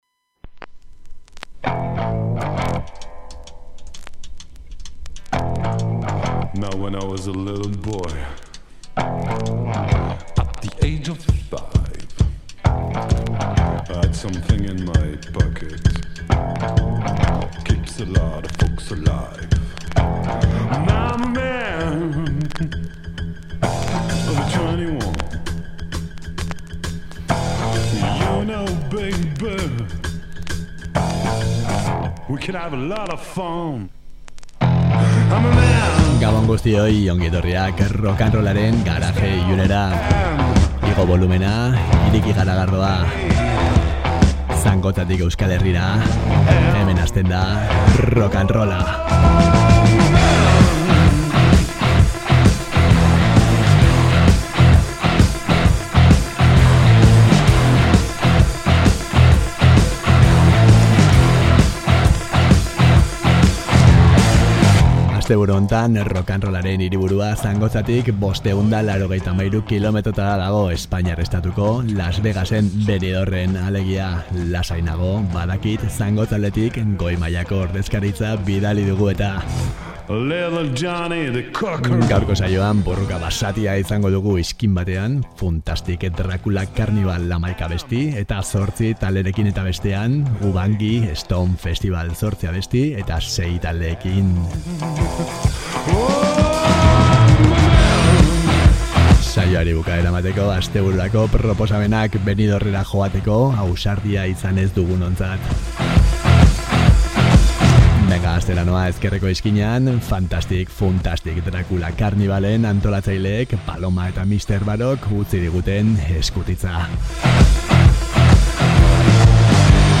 Ezkerreko txokoan Funtastic Dracula Carnival, Garage, R’n’R, Frat, Punk eta arauik gabeko 11 abesti eta 8 talderekin entzungo dira; eta bestalde eskubiko txokoan, Ubangi Stomp Festival, Rockabilly eta 50. eta 60. hamarkadetako 8 abesti eta 6 talderekin izango dituzue. Benidormen egoteko ausardia ez dugunontzat astebururako bi proposamen, Dictators eta Sexty Sexers.